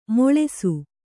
♪ moḷesu